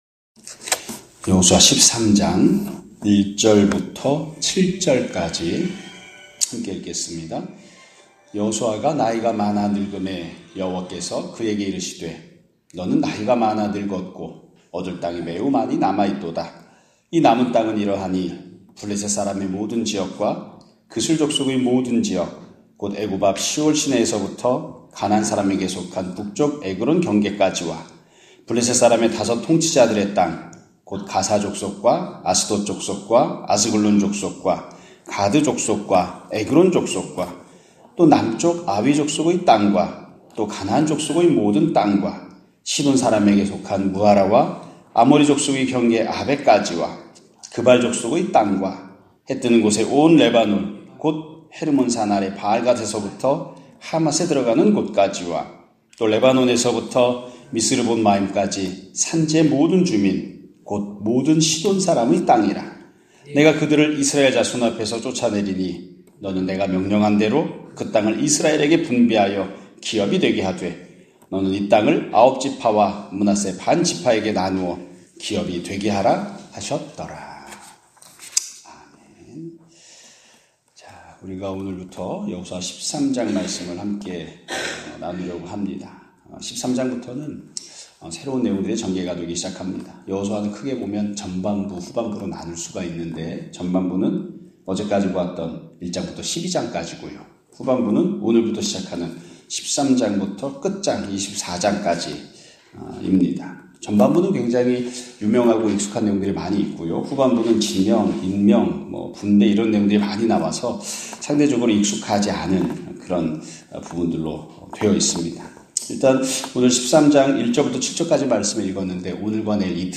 2024년 11월 14일(목요일) <아침예배> 설교입니다.